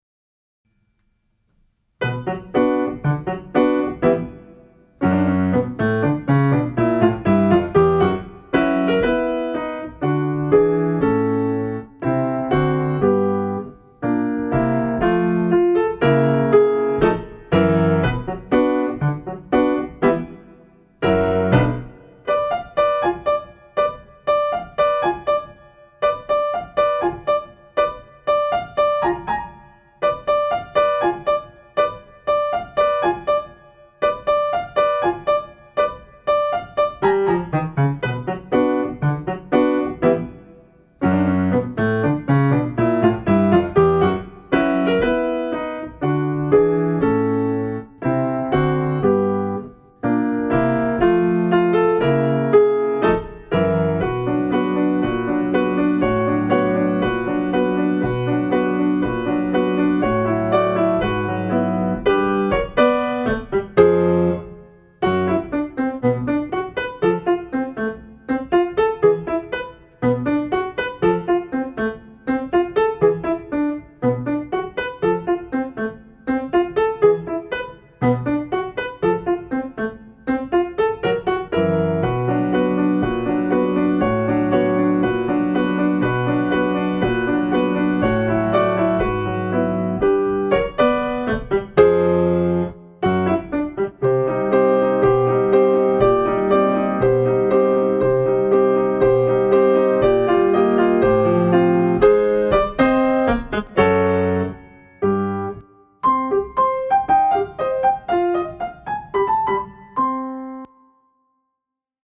【Instrumental】 mp3 DL ♪
BPM 120